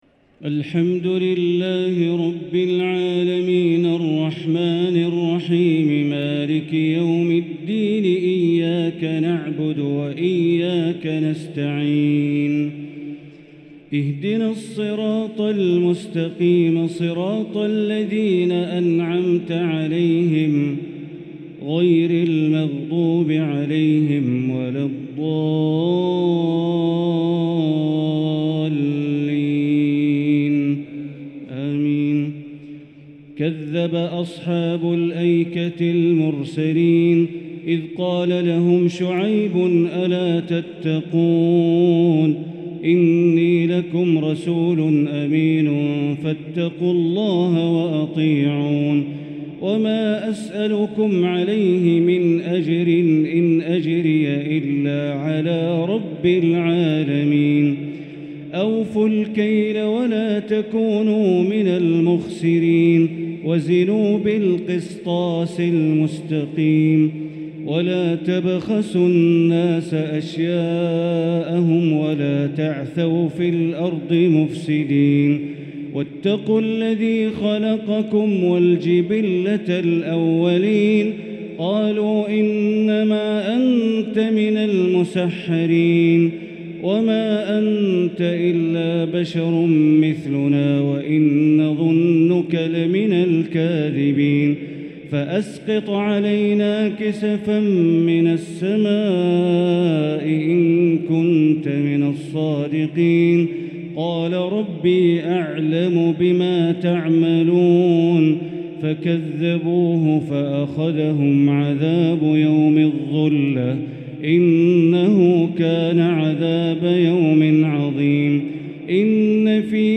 تراويح ليلة 23 رمضان 1444هـ من سورتي الشعراء {176-227} و النمل {1-44} taraweeh 23th night Ramadan1444H surah Ash-Shuara and An-Naml > تراويح الحرم المكي عام 1444 🕋 > التراويح - تلاوات الحرمين